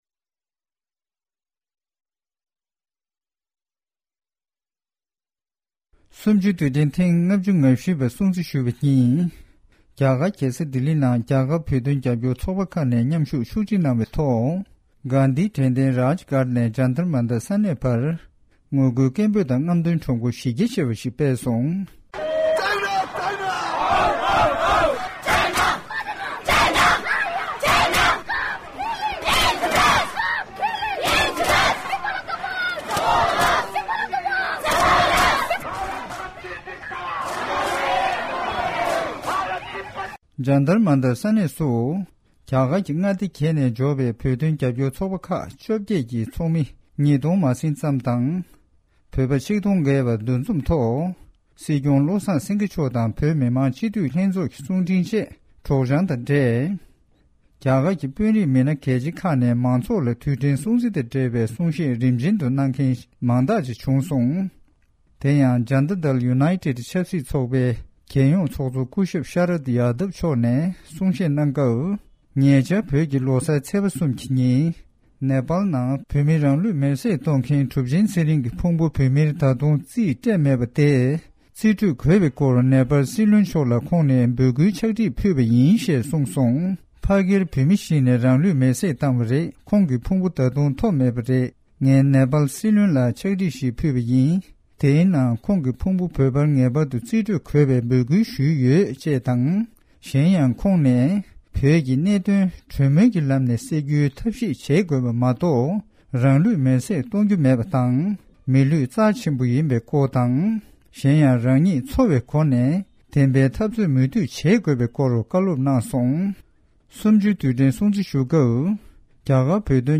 གནས་ཚུལ་ཞིག་གསན་གྱི་རེད།